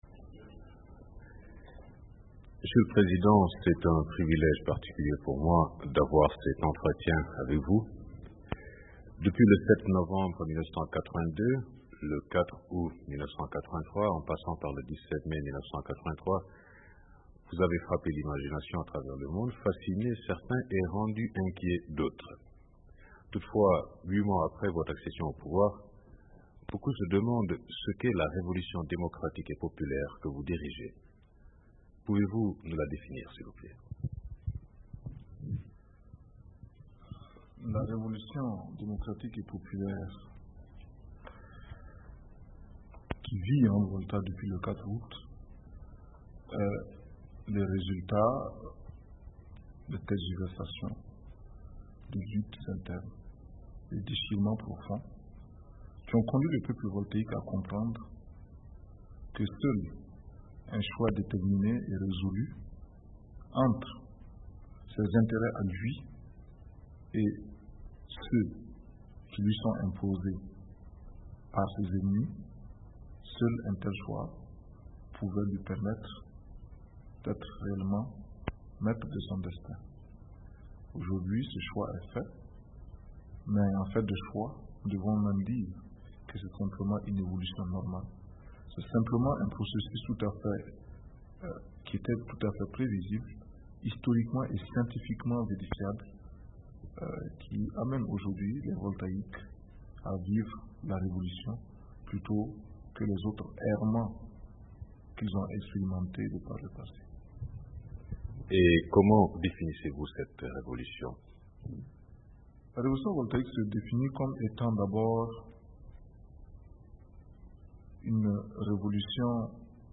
L'entretien a eu lieu au palais présidentiel à Ouagadou, en 1984